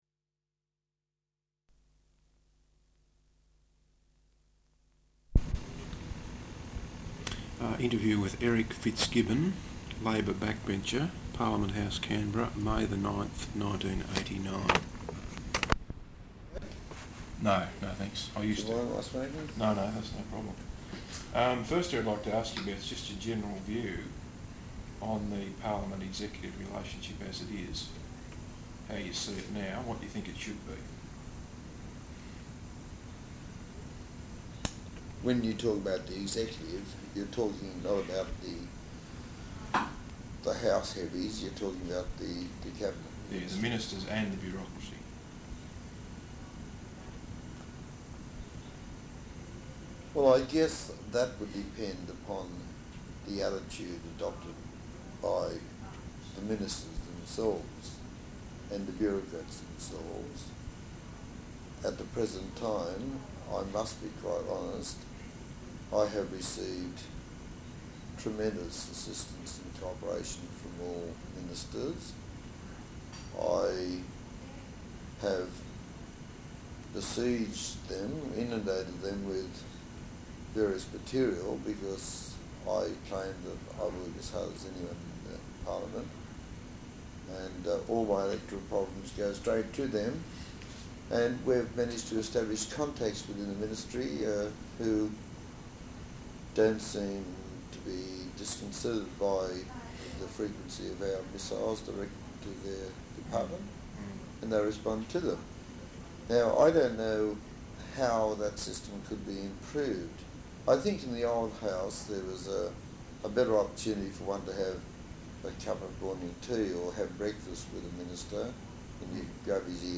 Interview with Eric Fitzgibbon, Labor Backbencher, Parliament House, Canberra, 9 May, 1989.